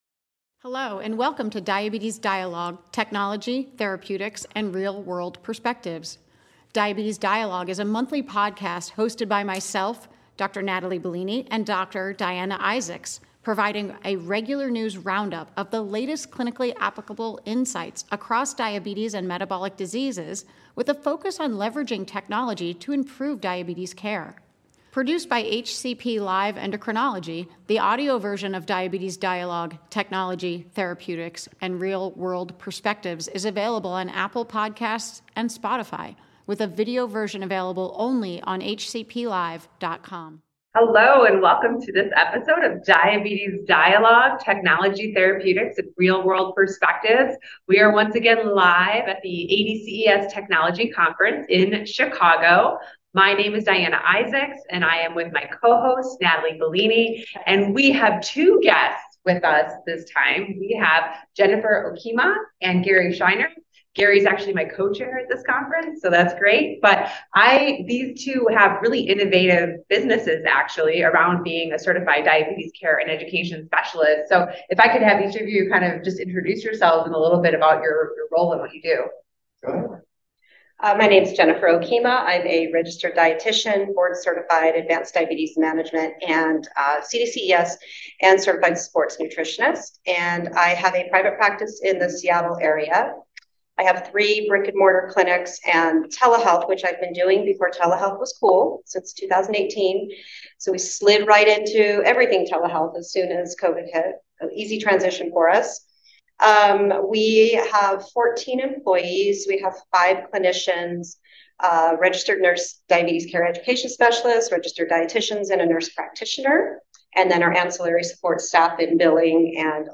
In their final episode from the ADCES 2nd Annual Diabetes Technology Conference